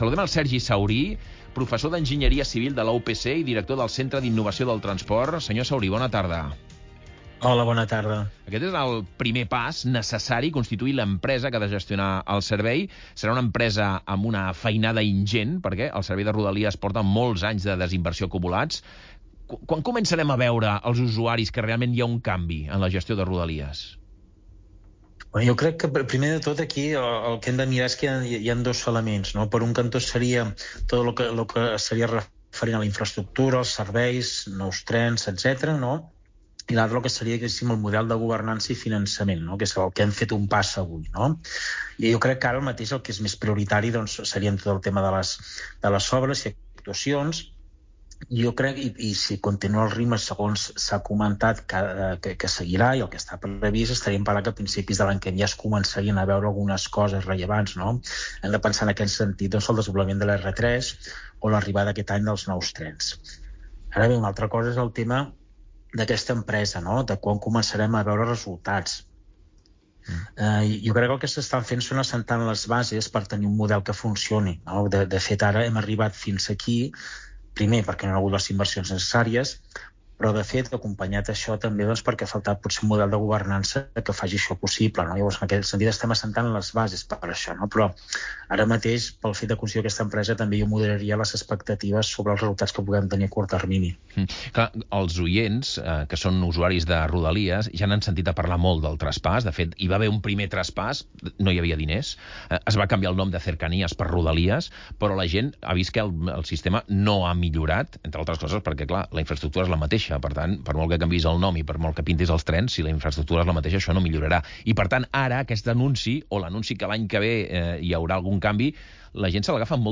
In an interview on the Catalunya Migdia news programme on Catalunya Ràdio